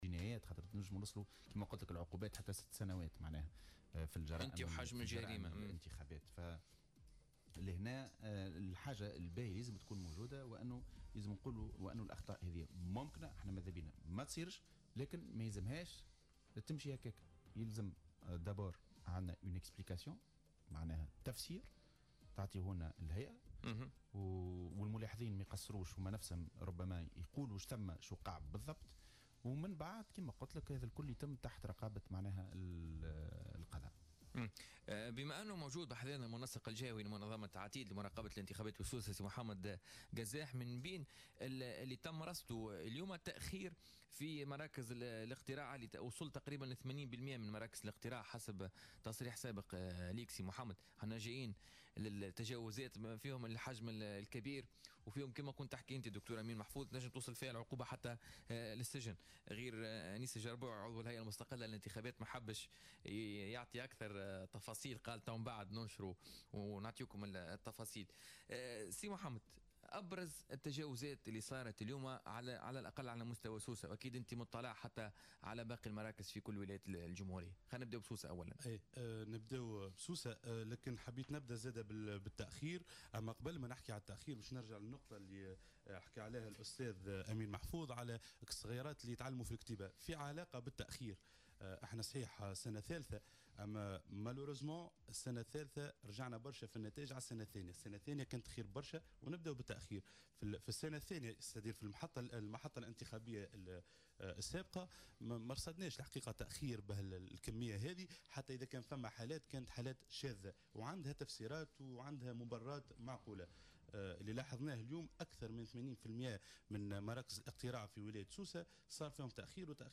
Dans une déclaration accordée à Jawhara FM ce dimanche 6 mai 2018